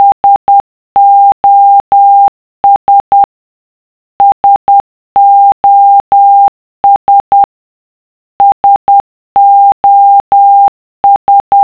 A dot means a short beep and a dash means a long beep.
SOS stands for "Save our Souls" and it was shortened to SOS.  This is very easy to hear or see in morese code as it is 3 short beeps, followed by 3 long beeps followed by 3 more short beeps.
SOS.wav